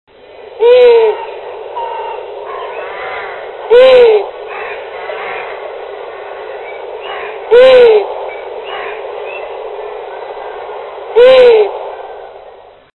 Gufo comune
Richiamo della femmina (f>)
Asio-otus-2.mp3